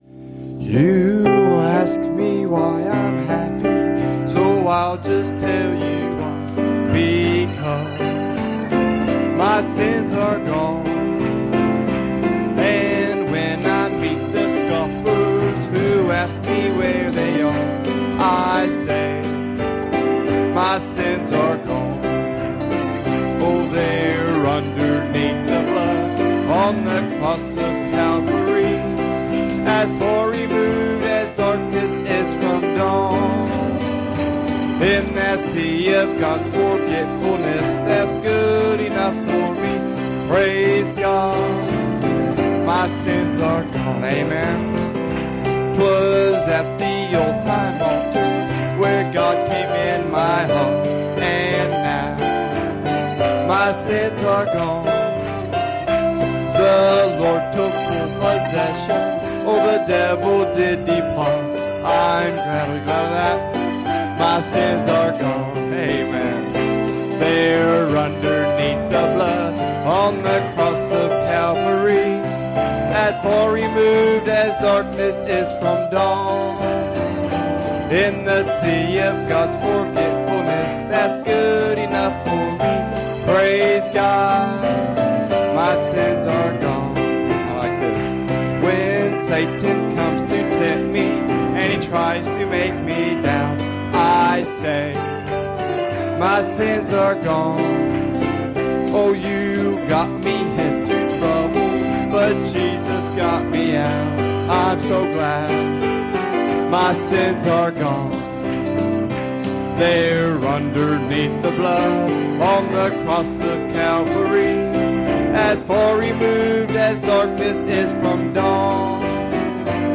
Passage: 1 Samuel 17:22 Service Type: Sunday Morning